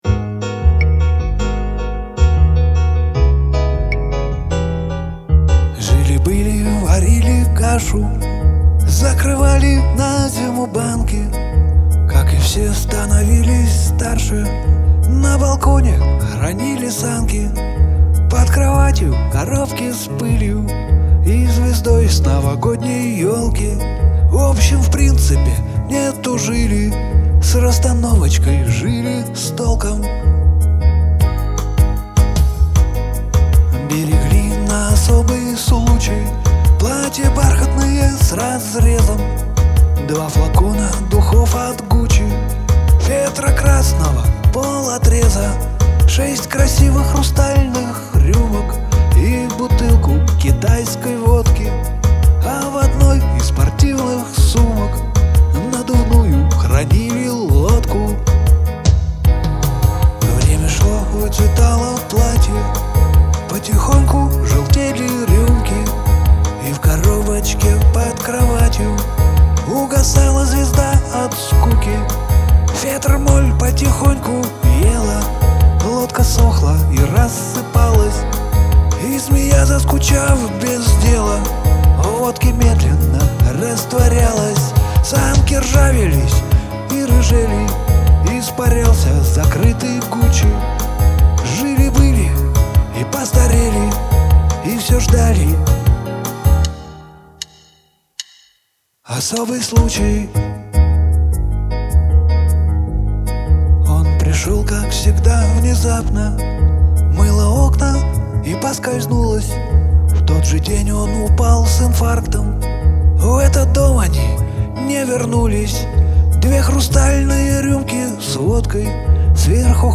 Может, кому-то пригодится в жизни и песенка в исп.